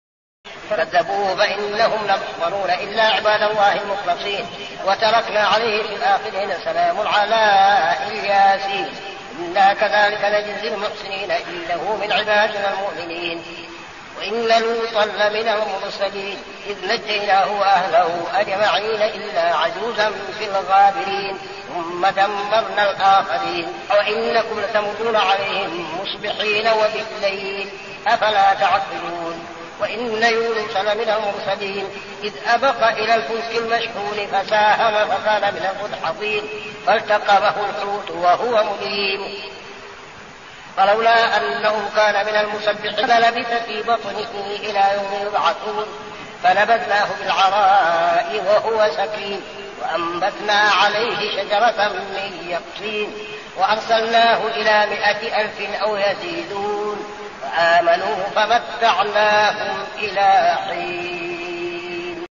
صلاة التراويح عام 1402هـ سورة الصافات 127-148 | Tarawih prayer Surah As-Saffat > تراويح الحرم النبوي عام 1402 🕌 > التراويح - تلاوات الحرمين